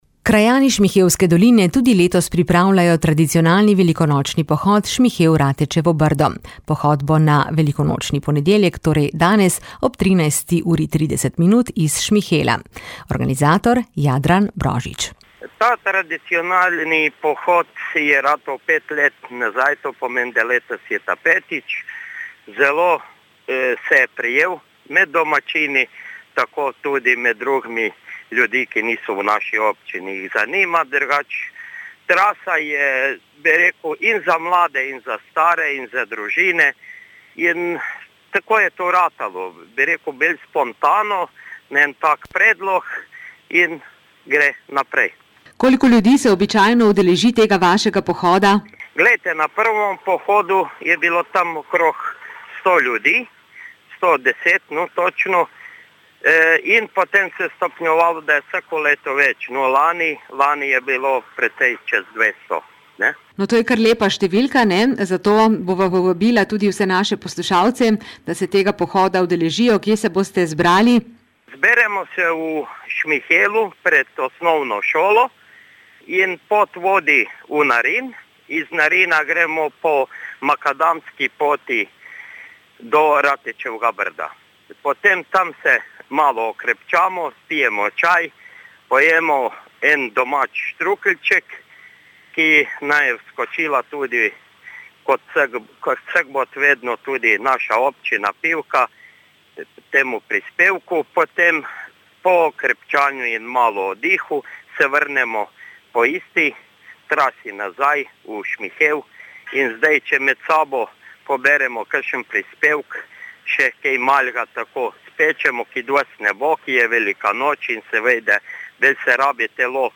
Krajani Šmihelske doline tudi letos organizirajo tradicionalni velikonočni pohod Šmihel – Ratečevo Brdo. Pohod bo na velikonočni ponedeljek 25. aprila ob 13. uri iz Šmihela. Več v pogovoru